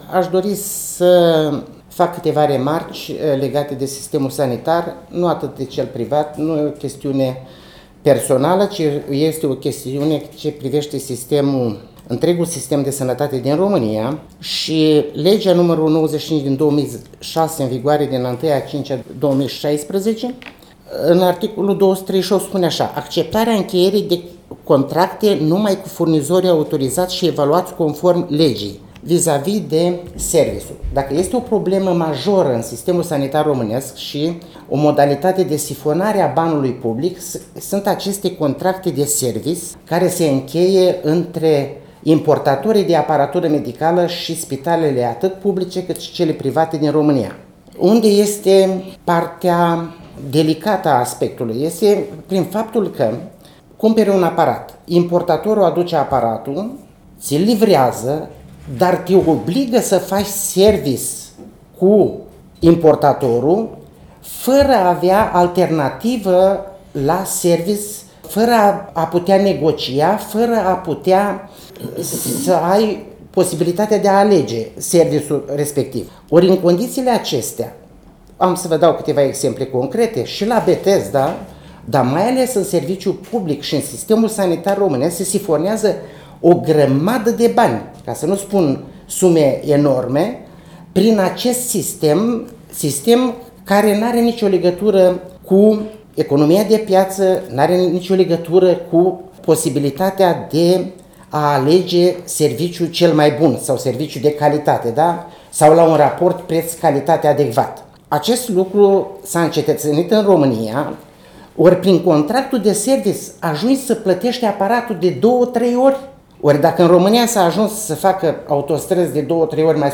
Spitalul Bethesda din Suceava – conferinţă de presă